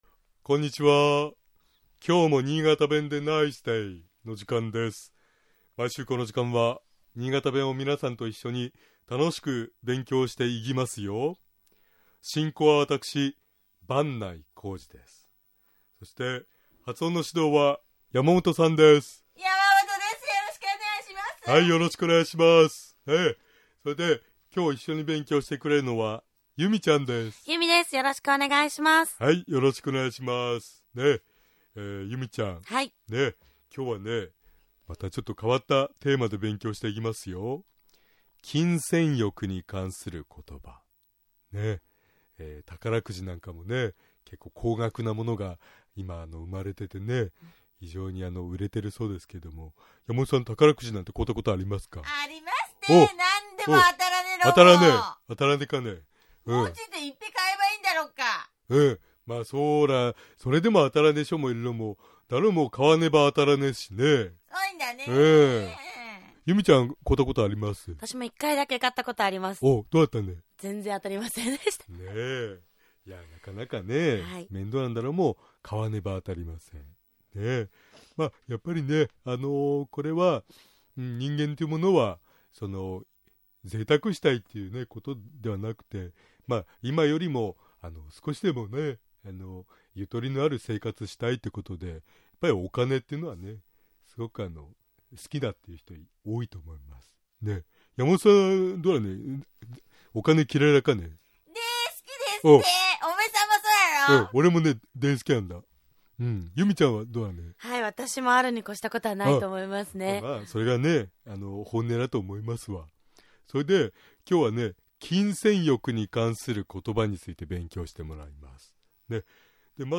従って、新潟弁で「お金を沢山もらった」と言う場合は、 「ぜんいっぺこともろた」という言い方になります。
尚、このコーナーで紹介している言葉は、 主に新潟市とその周辺で使われている方言ですが、 それでも、世代や地域によって、 使い方、解釈、発音、アクセントなどに 微妙な違いがある事を御了承下さい。